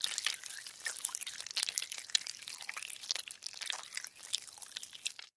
Шорох ползущего червяка по земле